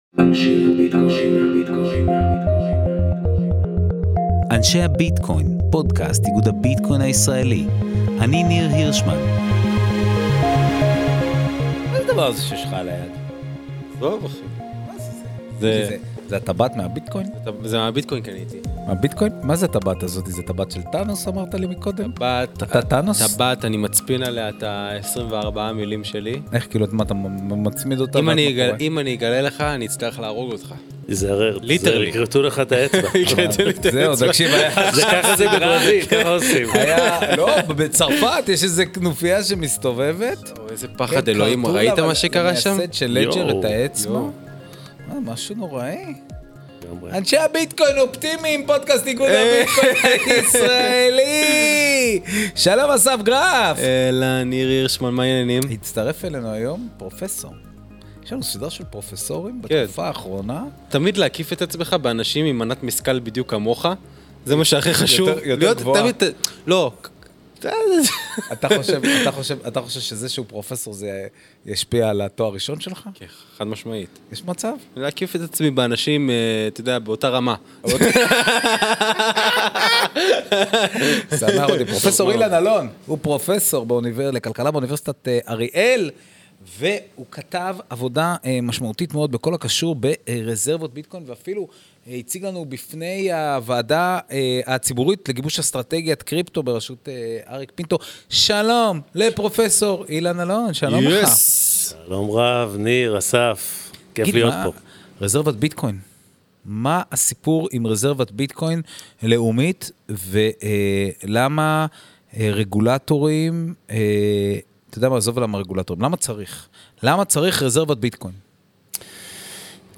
רזרבת ביטקוין לאומית – שיחה